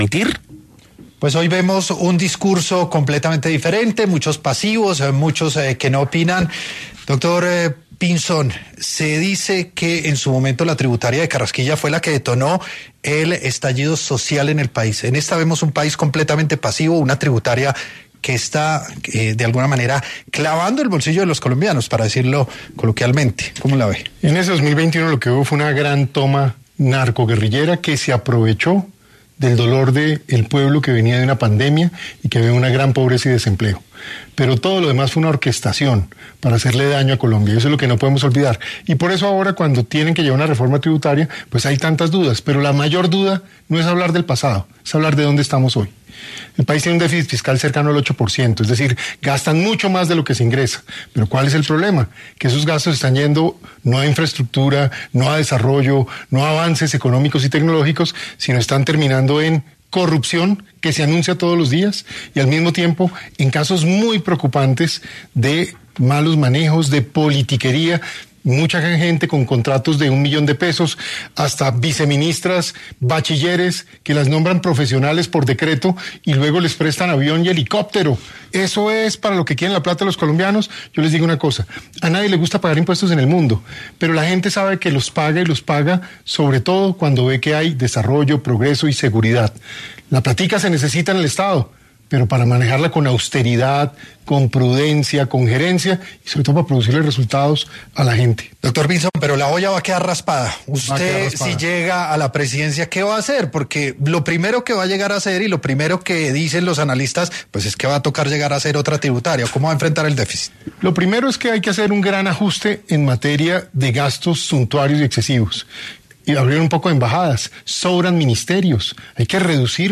Este martes, 2 de septiembre, el exministro de Defensa y precandidato presidencial Juan Carlos Pinzón llegó a los micrófonos de ‘Sin Anestesia’, un espacio novedoso e informativo liderado por La Luciérnaga de Caracol Radio y Red+ Noticias, donde arremetió contra la nueva reforma tributaria del gobierno de Gustavo Petro, al tiempo que revivió la controversia en torno al estallido social de 2021 durante el gobierno de Iván Duque.